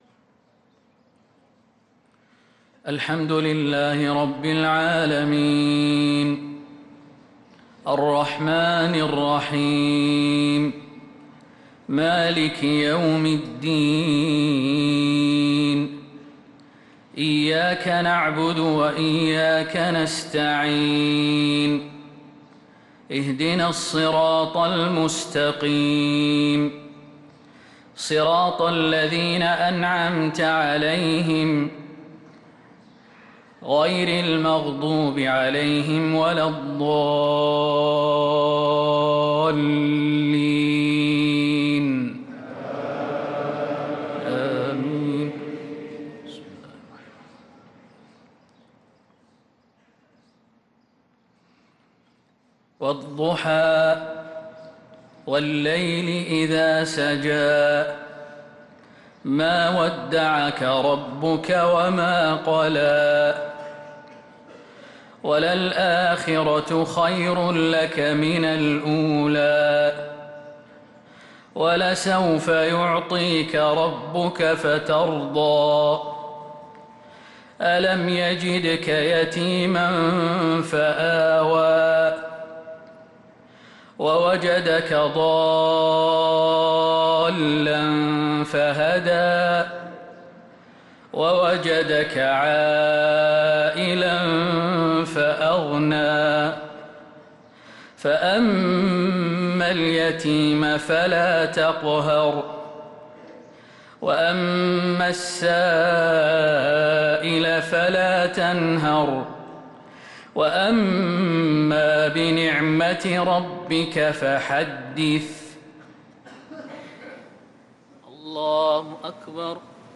صلاة المغرب للقارئ خالد المهنا 27 شعبان 1445 هـ